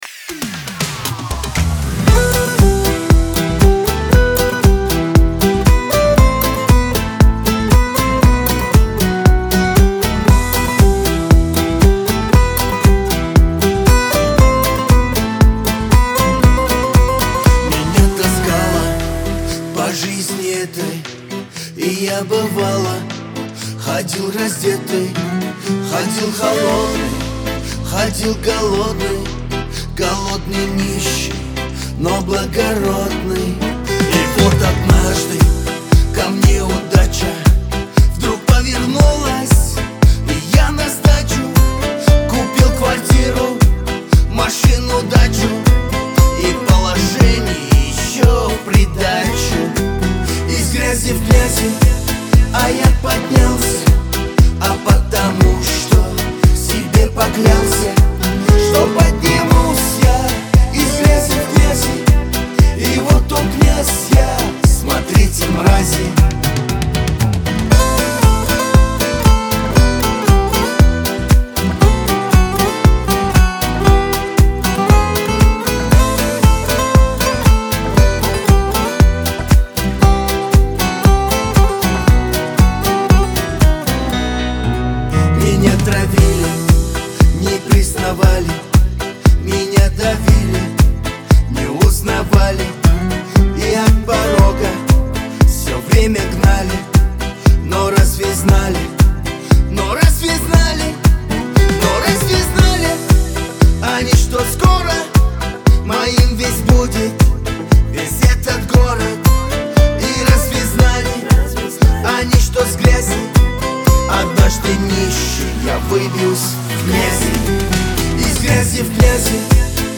Кавказ – поп